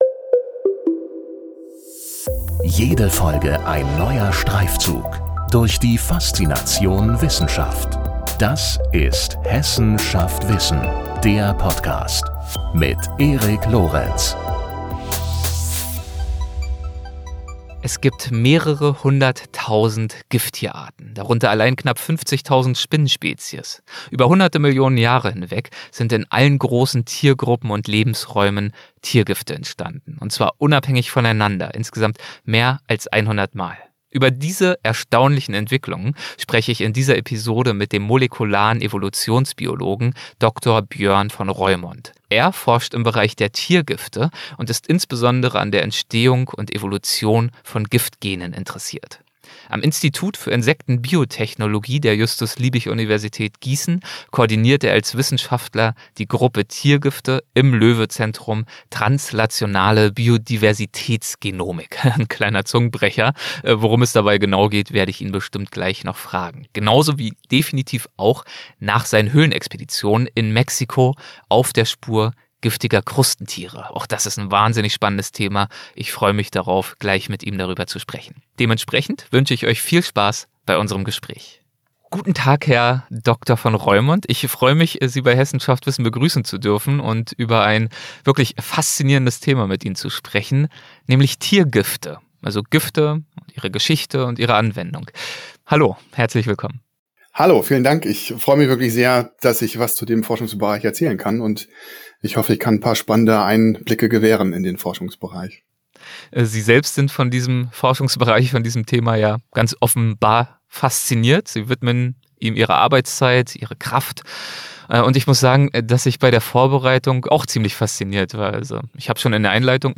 Wie er versucht den Geheimnissen dieser Giftgene auf die Spur zu kommen und wie es ihn dabei u.a. in eine mexikanische Unterwasserhöhle verschlagen hat, verrät er in diesem Gespräch.